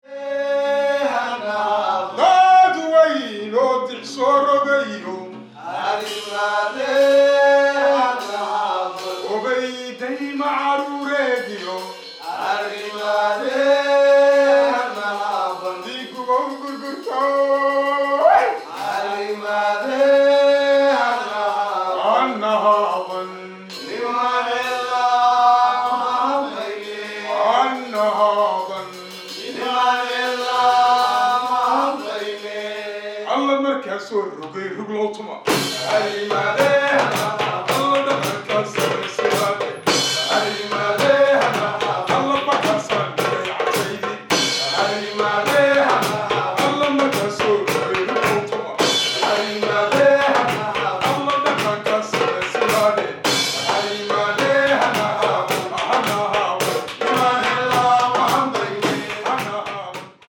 DANSE TRADITIONNELLE